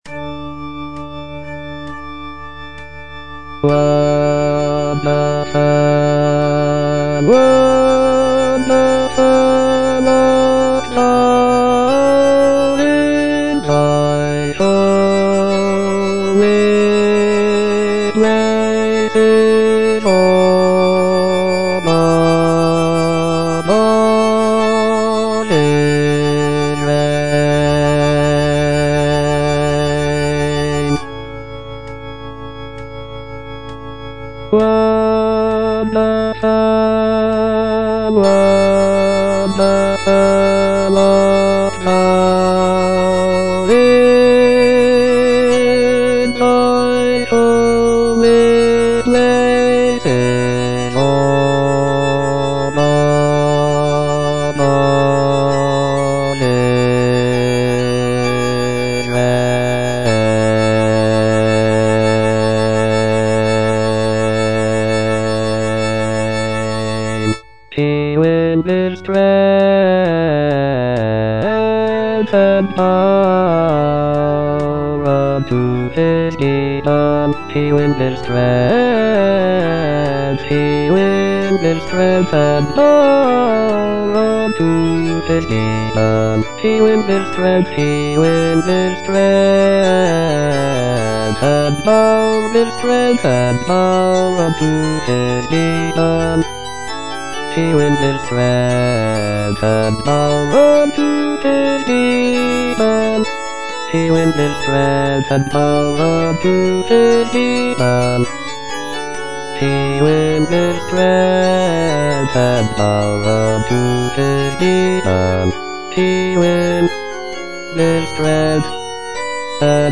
T. LINLEY - LET GOD ARISE Wonderful art thou in the holy places - Bass (Voice with metronome) Ads stop: auto-stop
"Let God arise" is a sacred choral work composed by Thomas Linley the younger, an English composer and conductor. Written in the Baroque style, the piece features a majestic and uplifting melody that conveys a sense of reverence and awe. The text of the work is taken from Psalm 68, which speaks of God's power and glory.